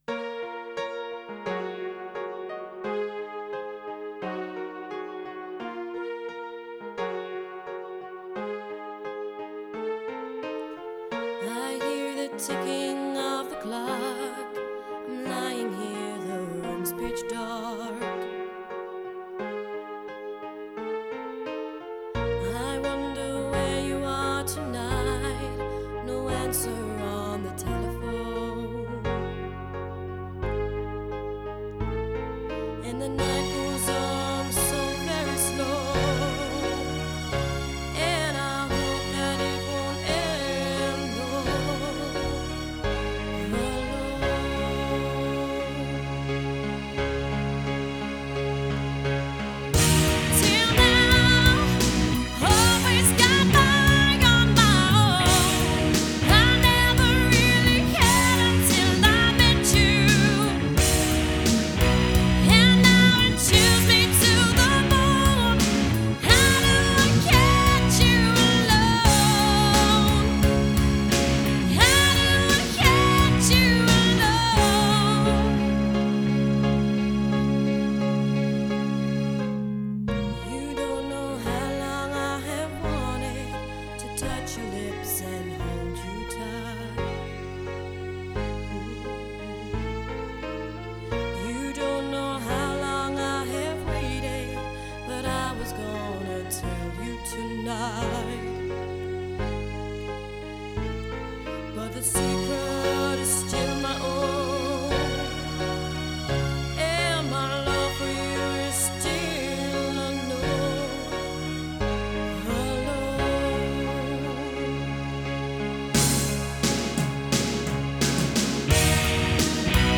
Feminine Edged Rock Band